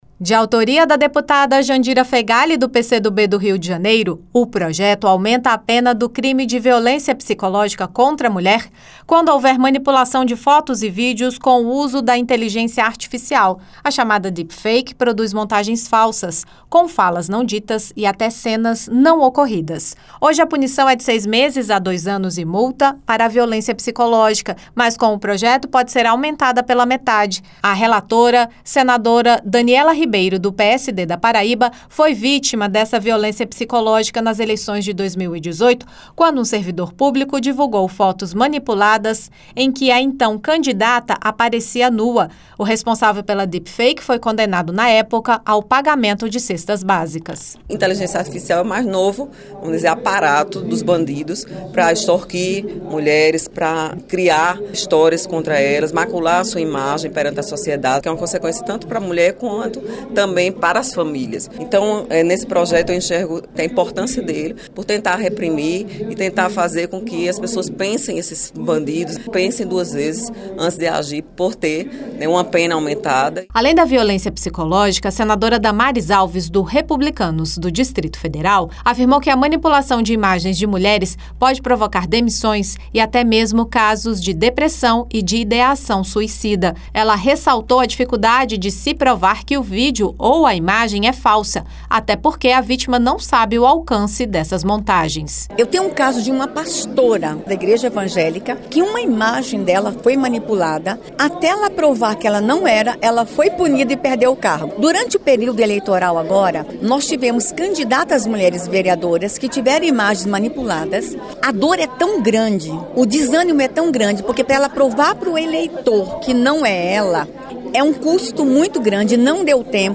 Plenário
Já a senadora Damares Alves (Republicanos-DF) destacou que as vítimas das chamadas deepfakes perdem o emprego e até têm ideação suicida por terem dificuldades de desmentir as manipulações.